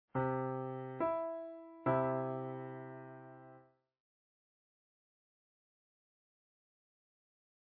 Please examine the numbers, letter names and sound of the interval of a major 10th in the key of C major. In this next idea we also compare the sound of the major 3rd to the major 10th.
Can you hear and sense the sweetness of the major tenth interval?